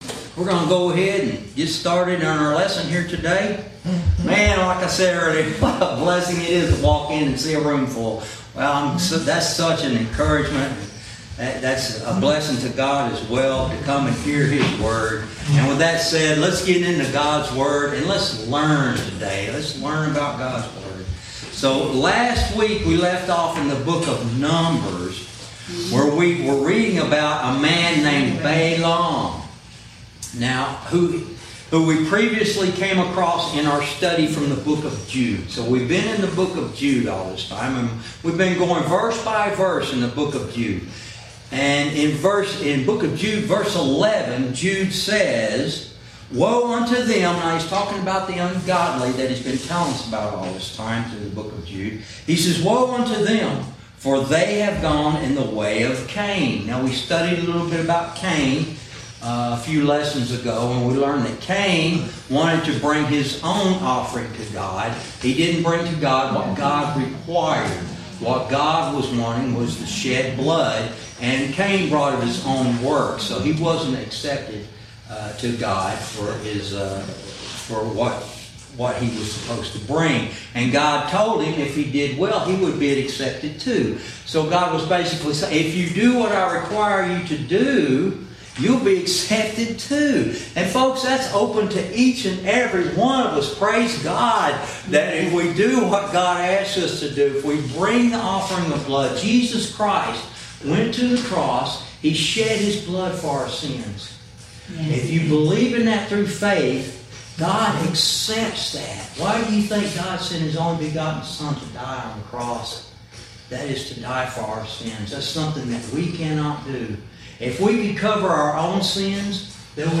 Verse by verse teaching - Lesson 41